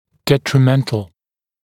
[ˌdetrɪ’mentl][ˌдэтри’мэнтл]вредный, пагубный